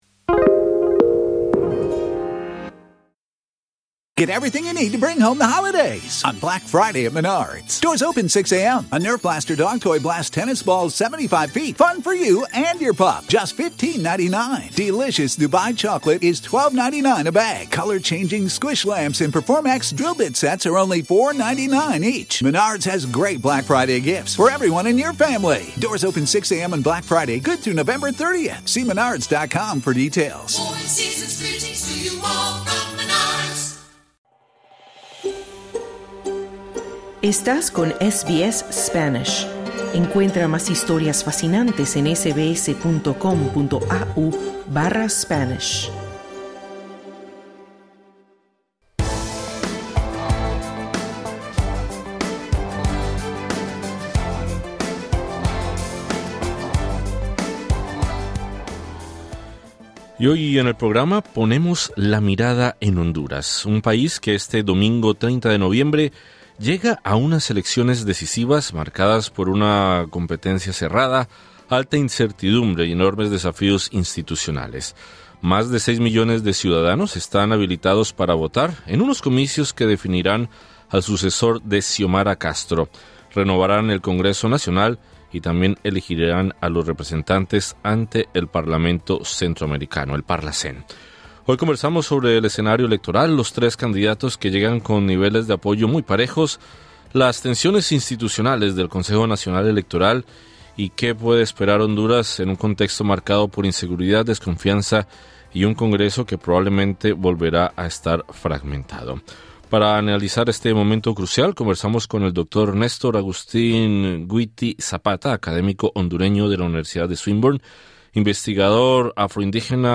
Análisis de las elecciones de Honduras 2025